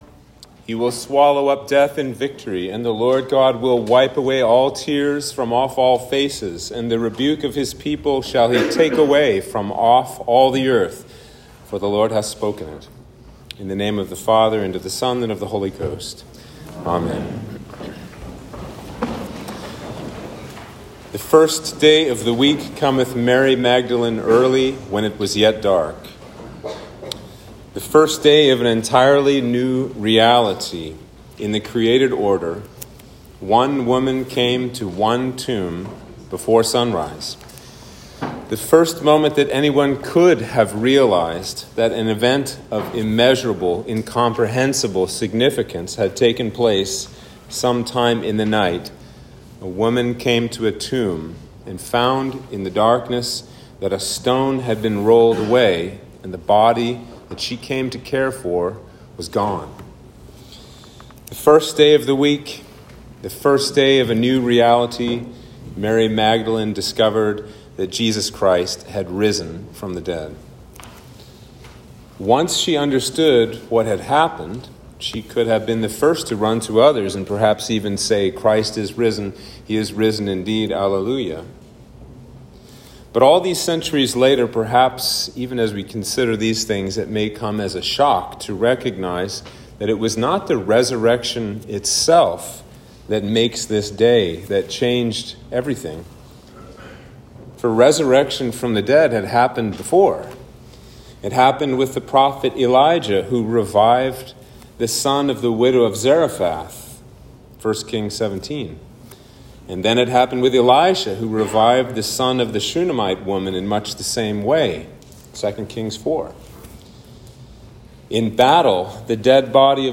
Sermon for Easter Day